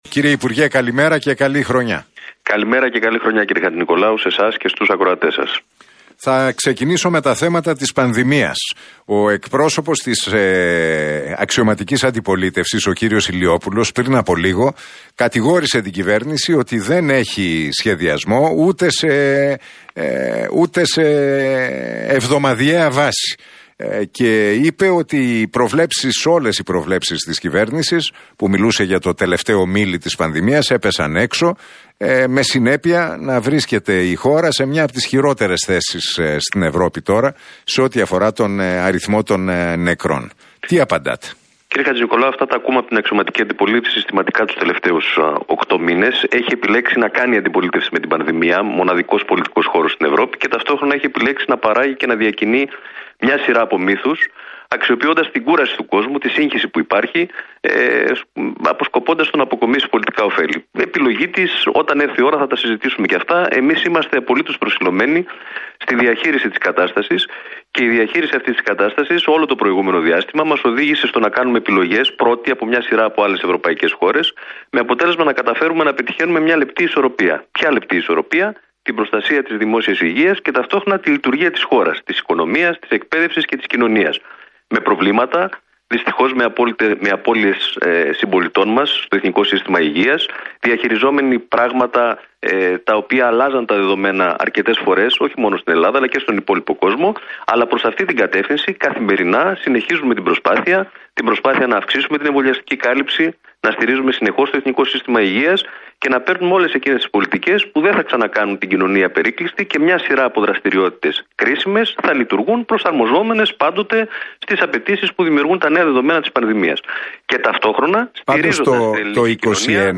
Ο Κυβερνητικός Εκπρόσωπος, Γιάννης Οικονόμου, μιλώντας στον Realfm 97,8 και στην εκπομπή του Νίκου Χατζηνικολάου δήλωσε ότι "